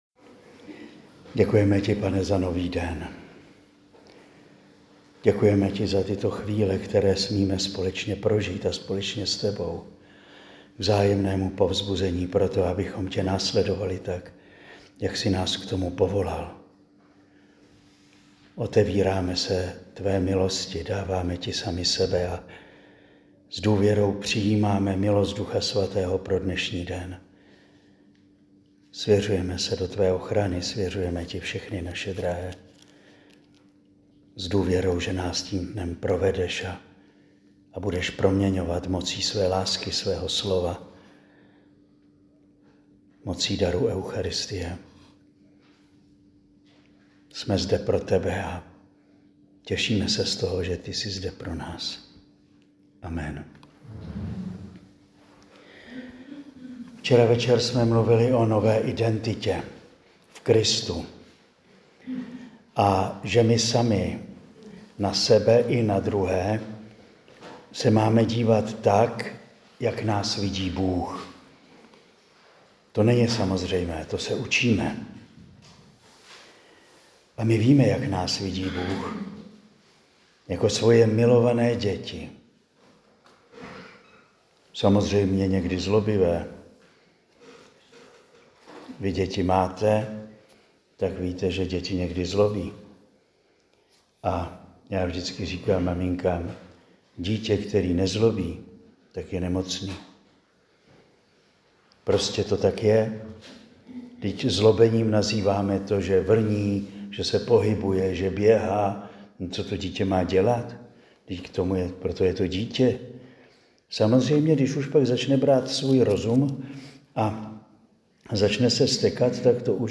Nyní si můžete poslechnout pátou promluvu.